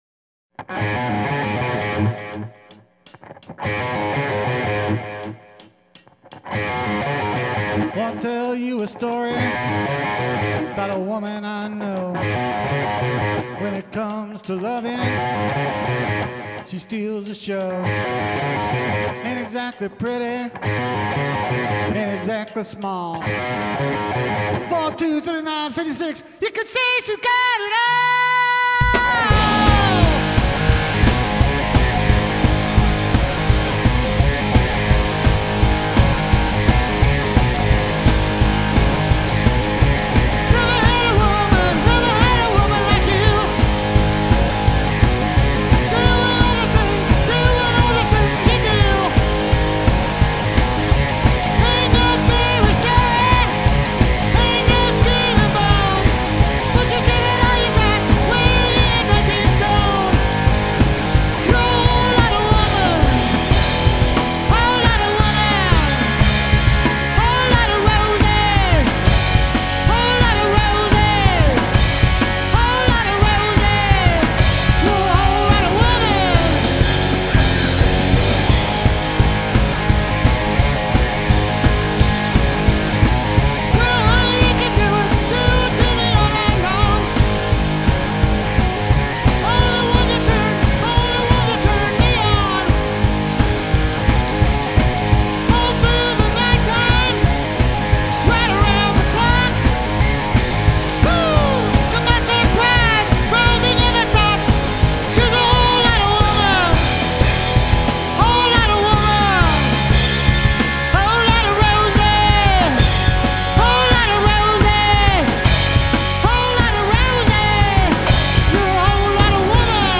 Vocals and Drums